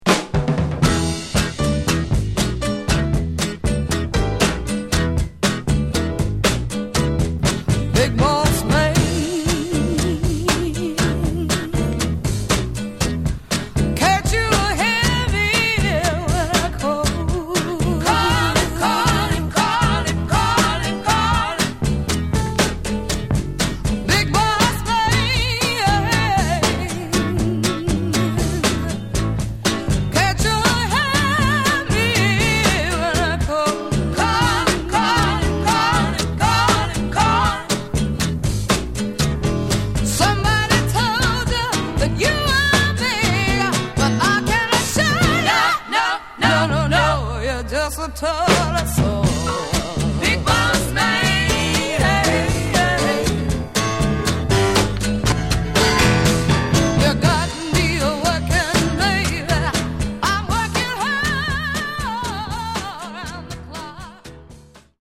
The audio itself is pristine Mint!